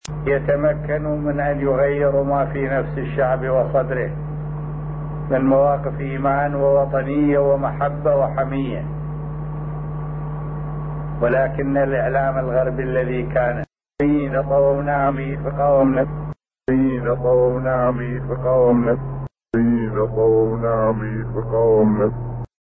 同様に、2003年のイラク戦争開始後に当時の大統領サダム・フセインが行った アラビア語でのスピーチにおいても、
という英語のリバース・スピーチが現れていた。